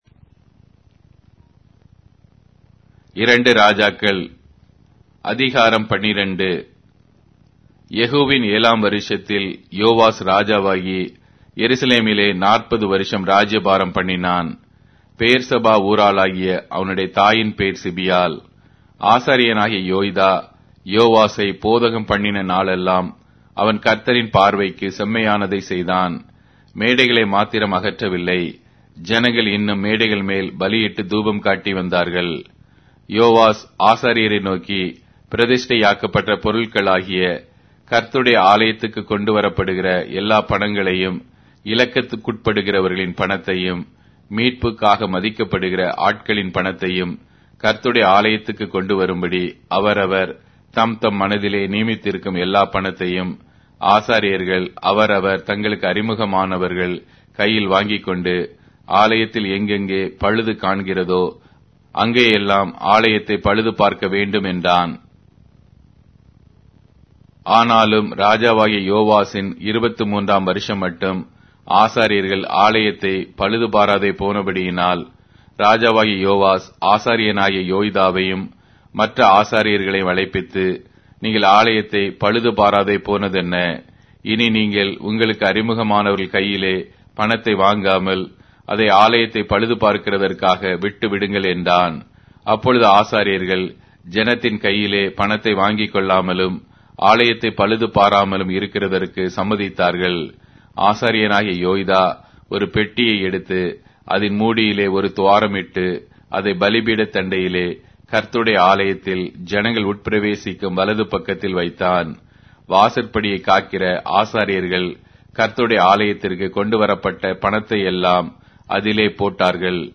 Tamil Audio Bible - 2-Kings 11 in Irvml bible version